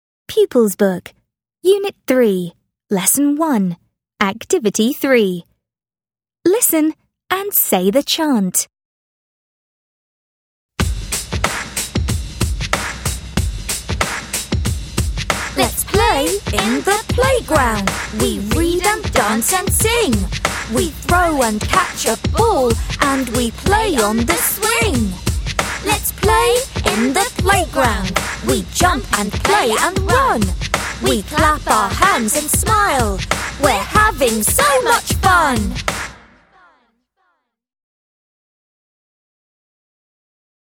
VOCABULARY CHANT